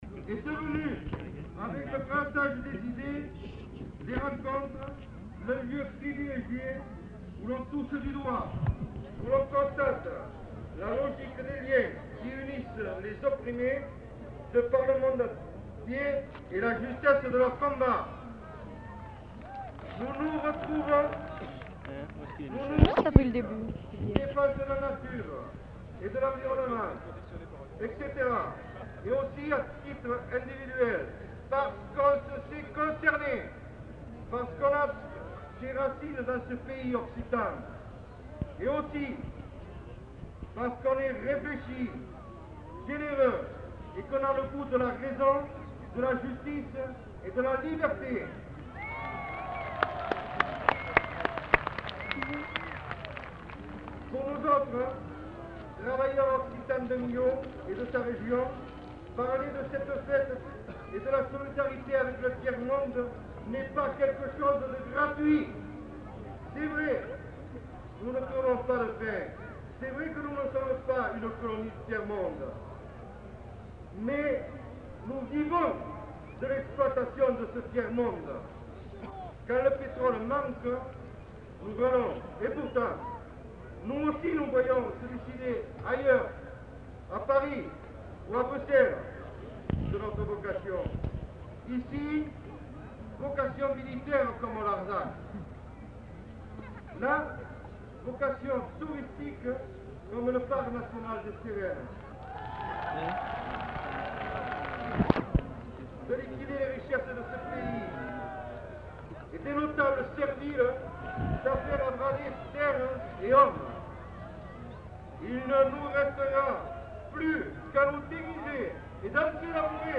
Numéro d'inventaire : 785-8 Plage CD/Page recueil : 8 Durée/Pagination : 15min 53s Dép : 12 Lieu : [sans lieu] ; Aveyron Date : 1974 Genre : parole Notes consultables : Les allocuteurs ne sont pas identifiés. Ecouter-voir : archives sonores en ligne Contenu dans [enquêtes sonores] Larzac 1974